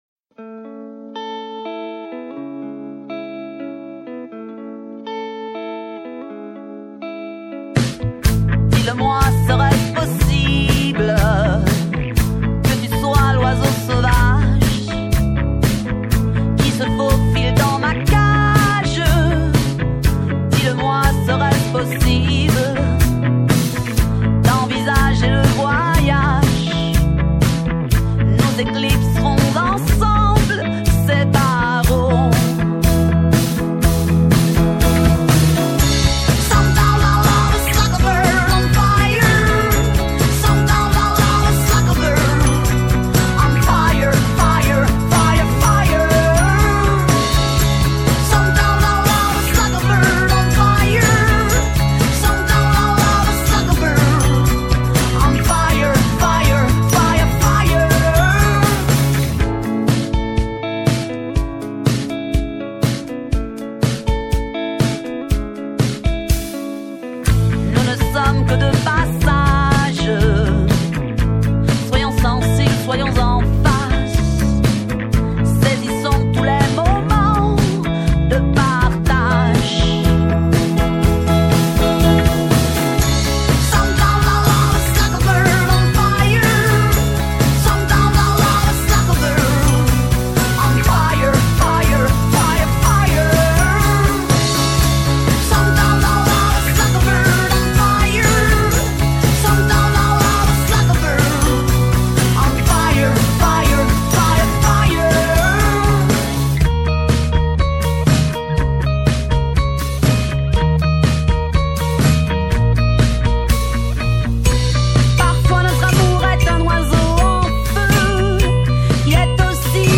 Entretien avec la chanteuse d’origine costarmoricaine